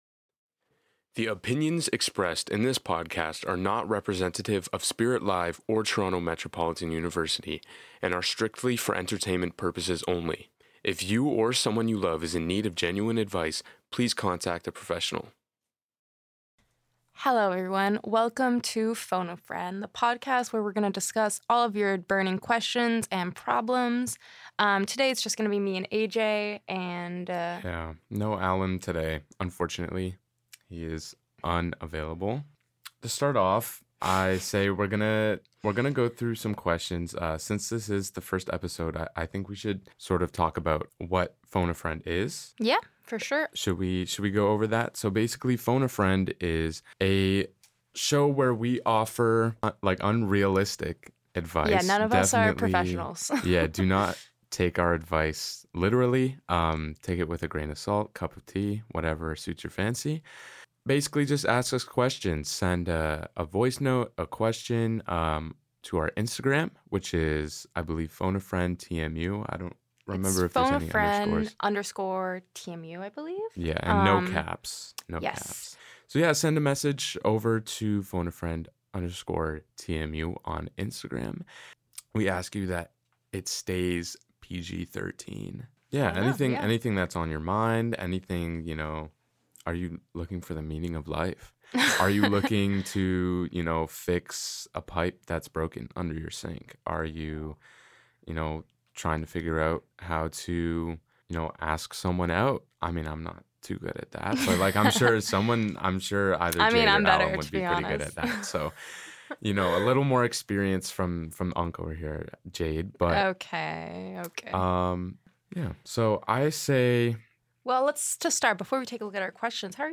Topics can range from Toronto Transit to school to maybe even, love. Every other week, listeners share their burning questions through calling or messaging Phone a Friend on Instagram where we will then give our absolute best possible advice LIVE.